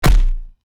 body_hit_large_76.wav